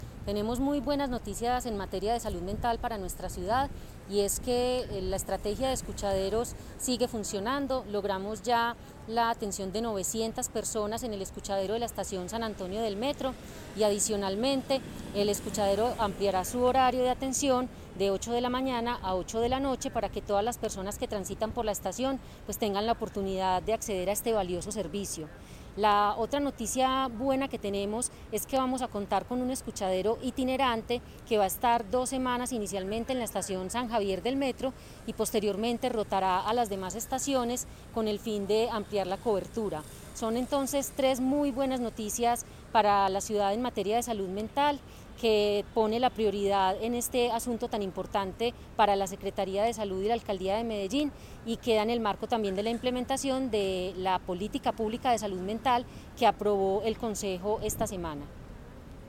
|| Audio || Palabras de Natalia López Delgado, subsecretaria de Salud Pública.